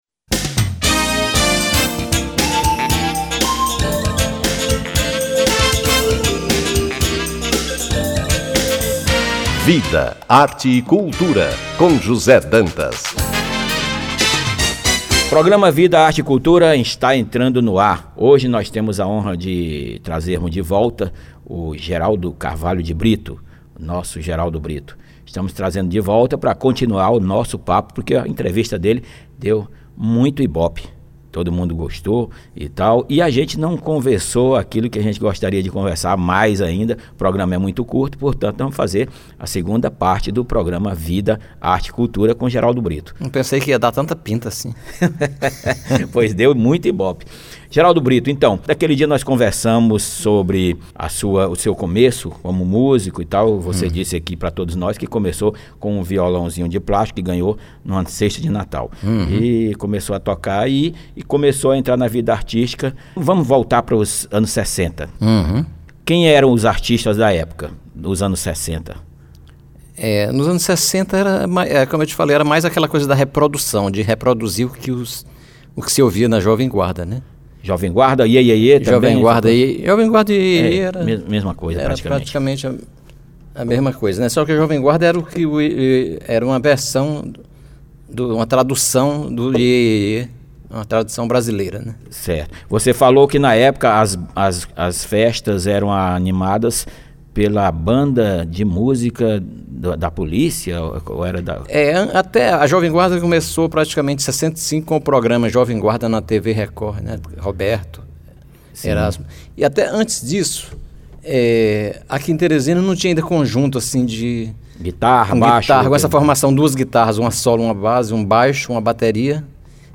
Entrevista (PODCAST)